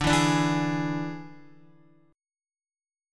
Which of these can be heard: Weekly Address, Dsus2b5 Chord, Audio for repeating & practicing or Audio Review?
Dsus2b5 Chord